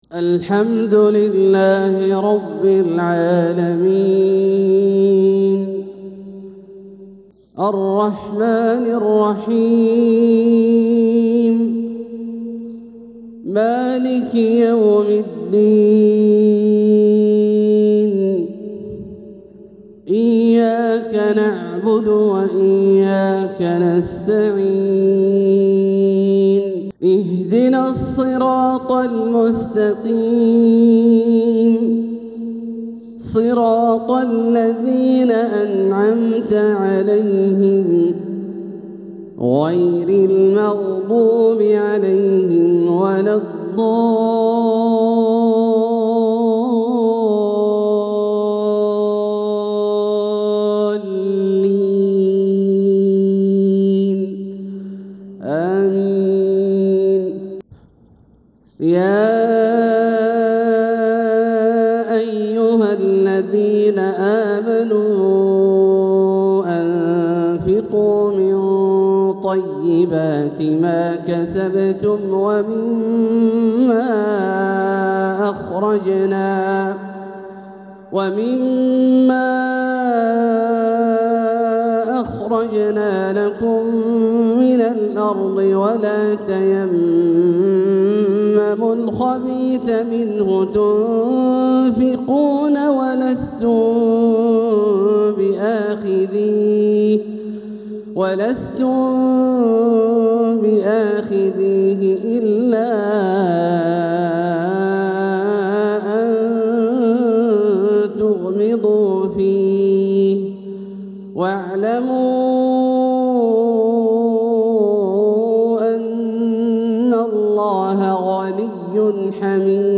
تلاوة أوتيت من الحسن كل شيء لأعجوبة الحرم من سورة البقرة | فجر ٥-٥-١٤٤٦ هـ > ١٤٤٦ هـ > الفروض - تلاوات عبدالله الجهني